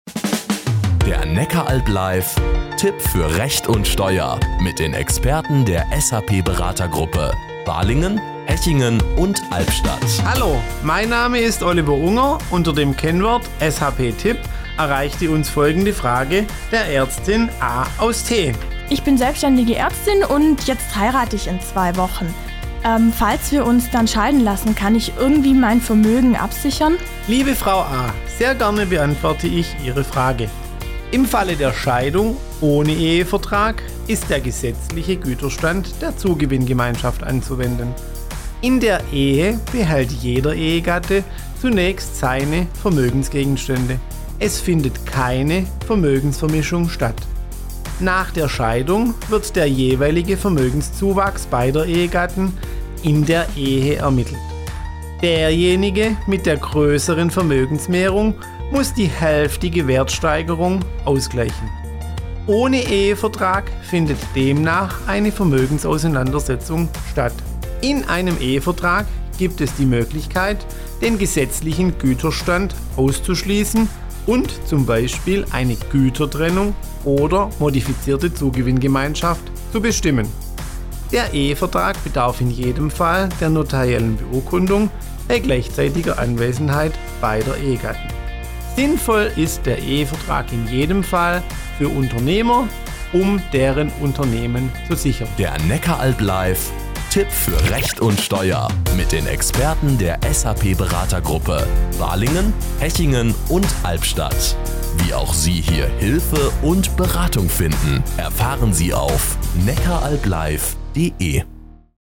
Radiospots